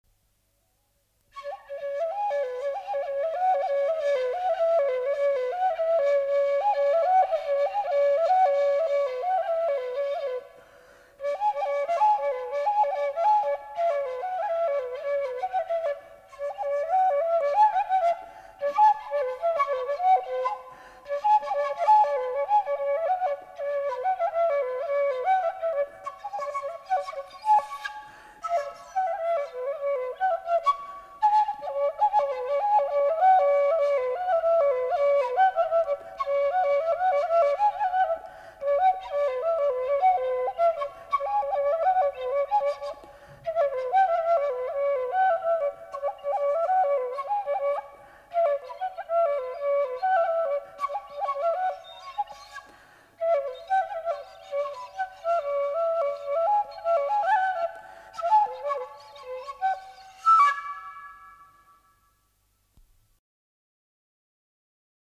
Голоса уходящего века (Курское село Илёк) Поленька (дудка, инструментальный наигрыш)
09_Наигрыш.mp3